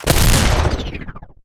weapon_railgun_001.wav